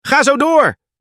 ga zo door sound effects